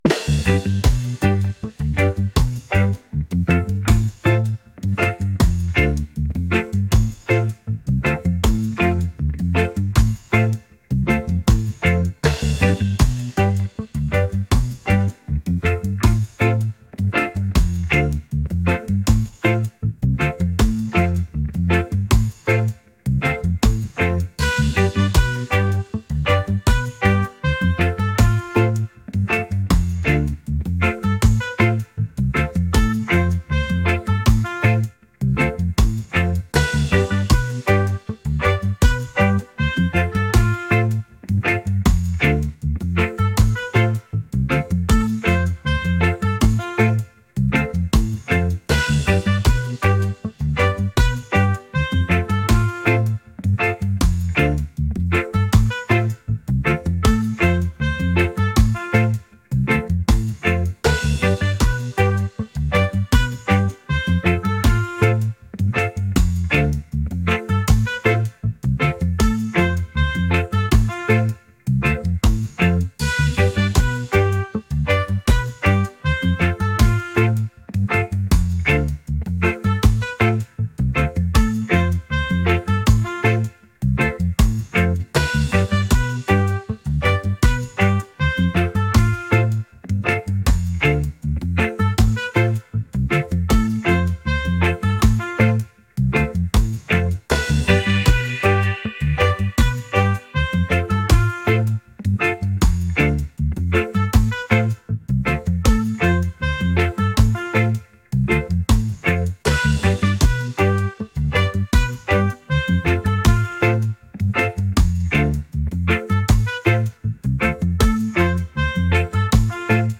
reggae | positive | upbeat